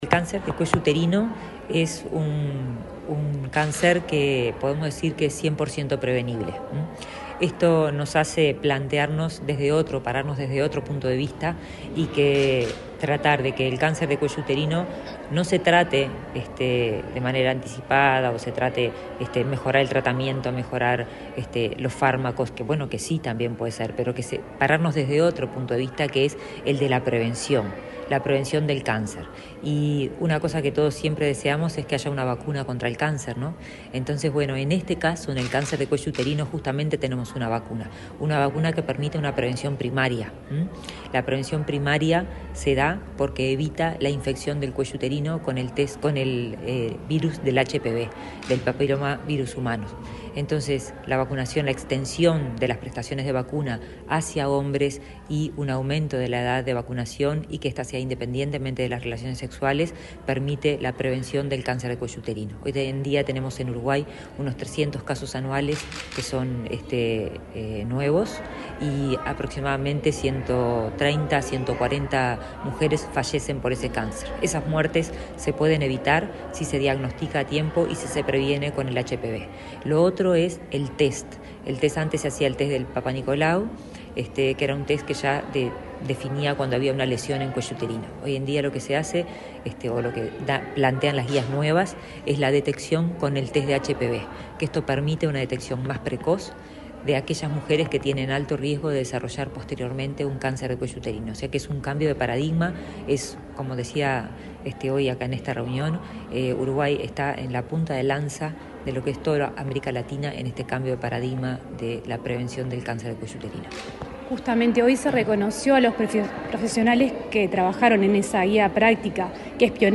Entrevista a la ministra de Salud Pública, Karina Rando
La ministra de Salud Pública, Karina Rando, dialogó con Comunicación Presidencial, acerca de los cambios en los estudios para detectar el cáncer de